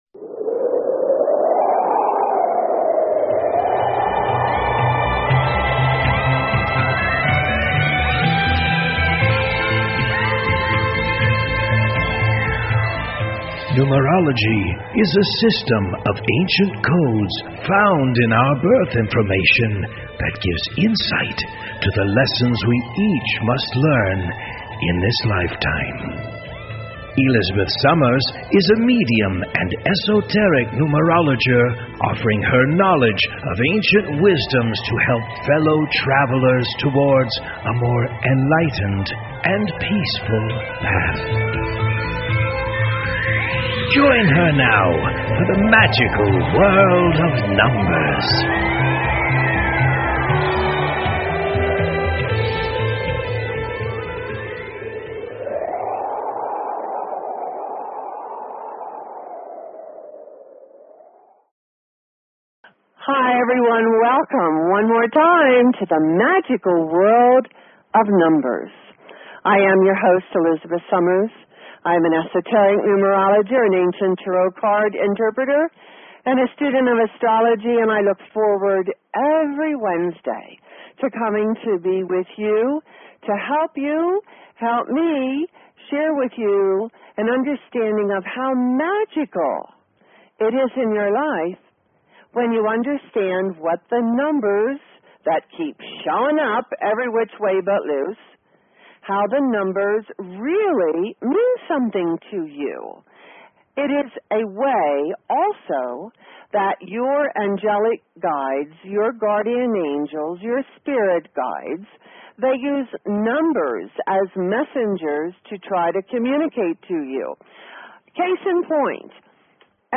Talk Show Episode, Audio Podcast, Magical_World_of_Numbers and Courtesy of BBS Radio on , show guests , about , categorized as